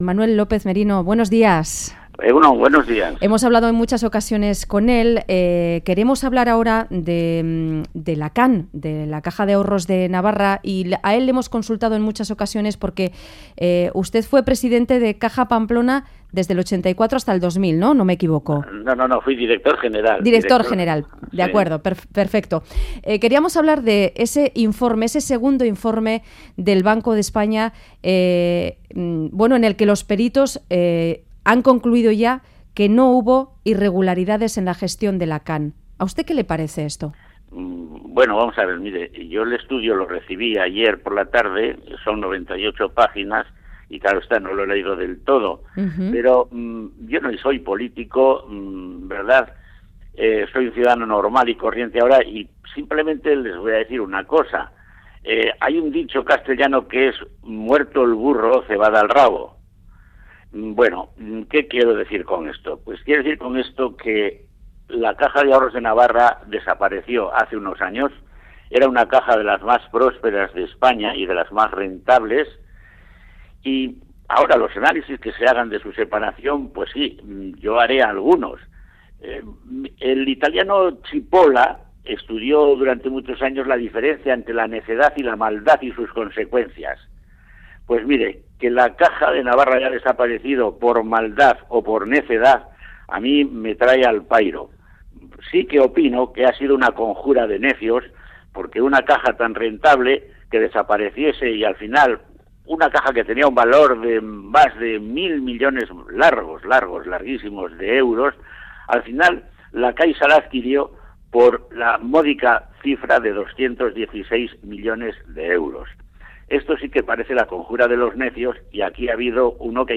En entrevista al Boulevard de Radio Euskadi